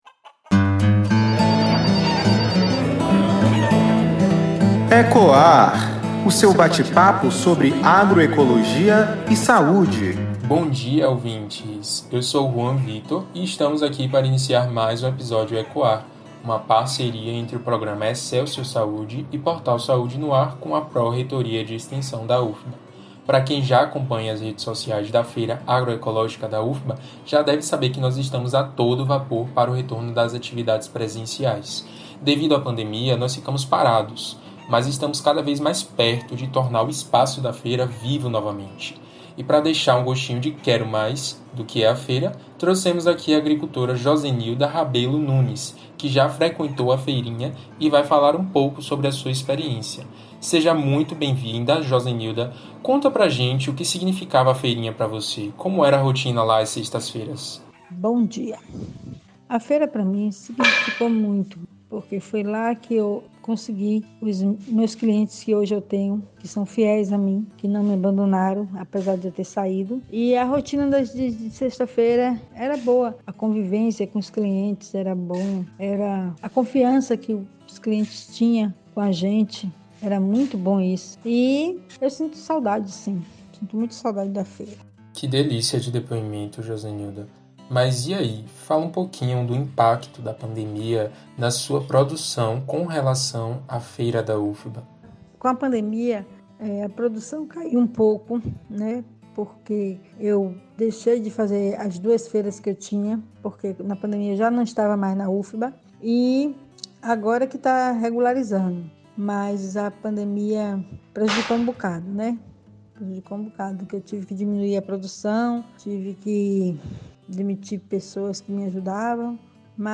Entrevistada: